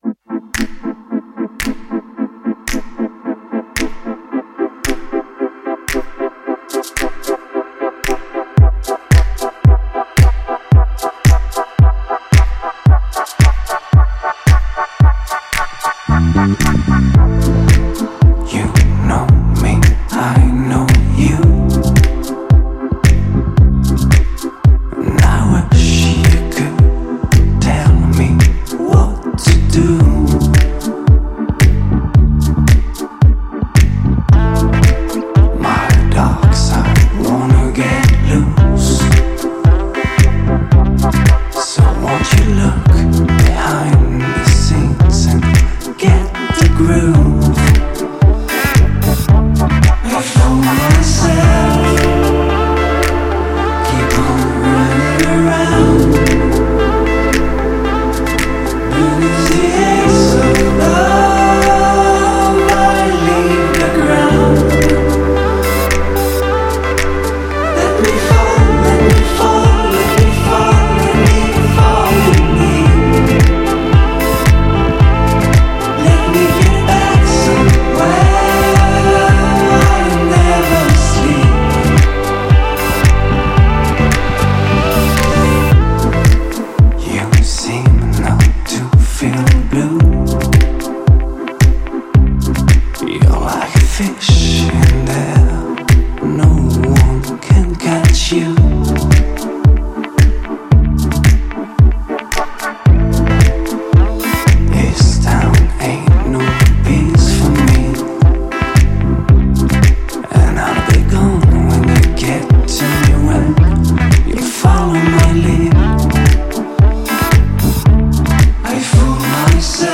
آهنگ دنس
آهنگ الکترونیک